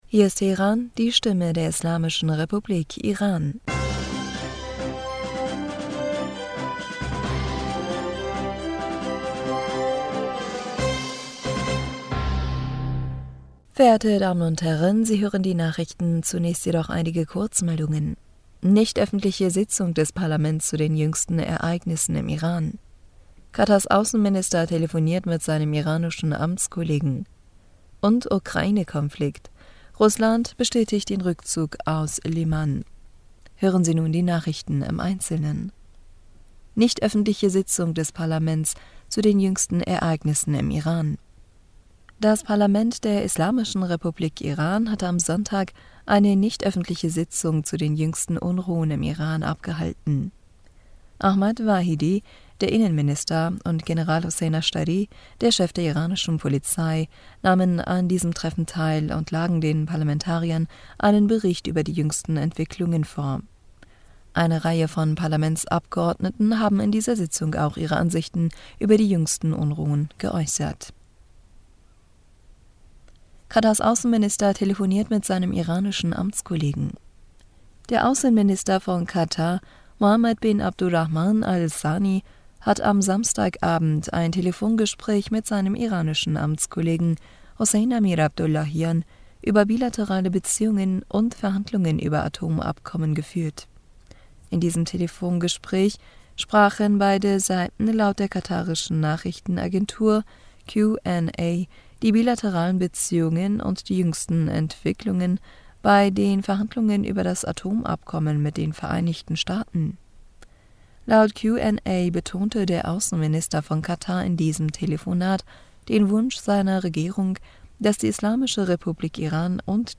Nachrichten vom 02. Oktober 2022